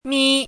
chinese-voice - 汉字语音库
mi1.mp3